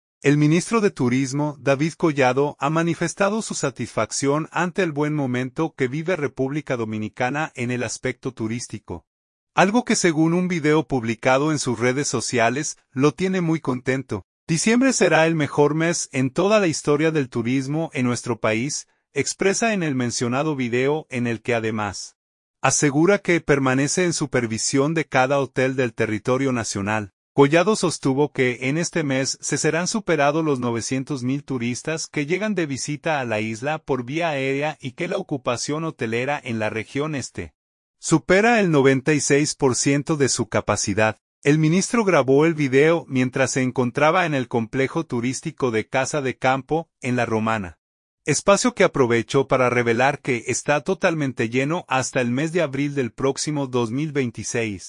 El ministro grabó el video mientras se encontraba en el complejo turístico de Casa de Campo, en La Romana, espacio que aprovechó para revelar que está totalmente lleno hasta el mes de abril del próximo 2026.